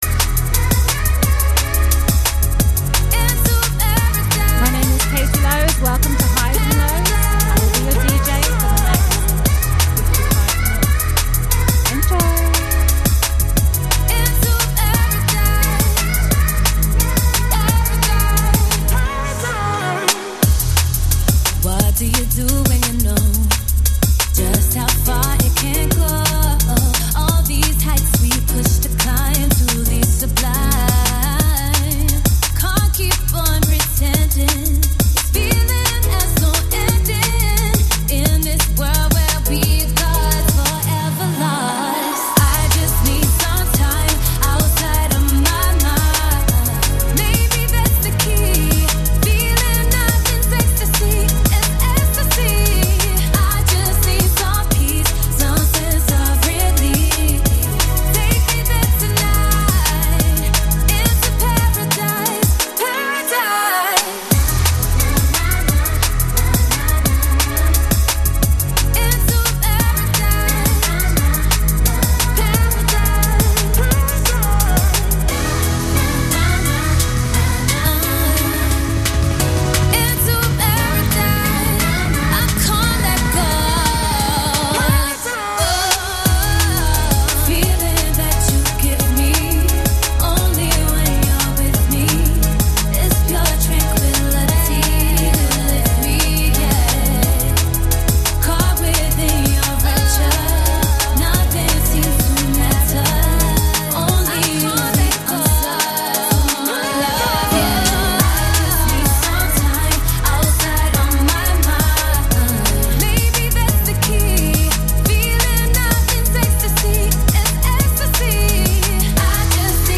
drum and bass 90s to now